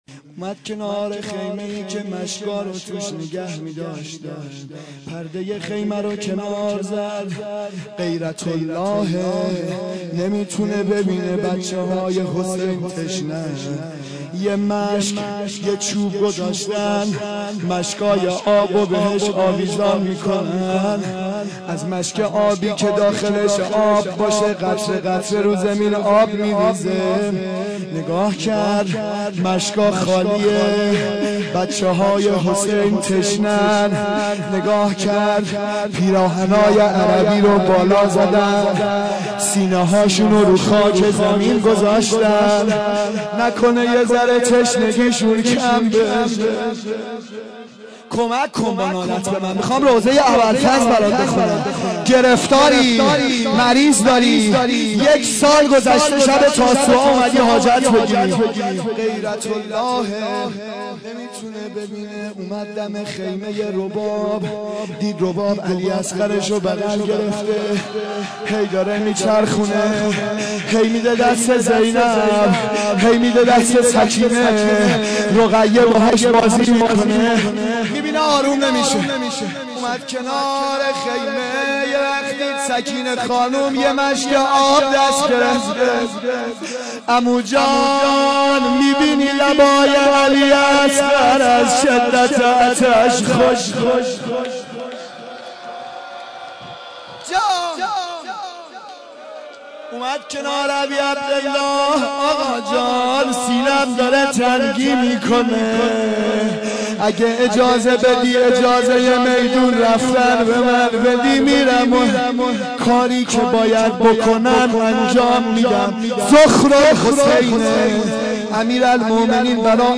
روضه شب نهم محرم 1391